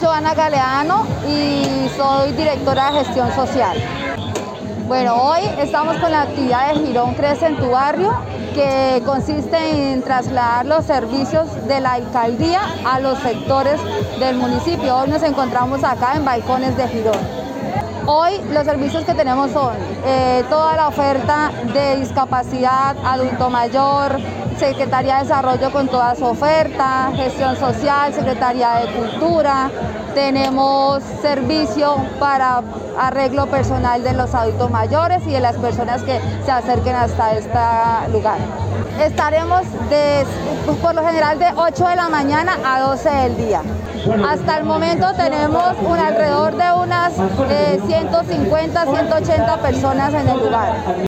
Johanna Galeano - Directora de Gestión Social Girón.mp3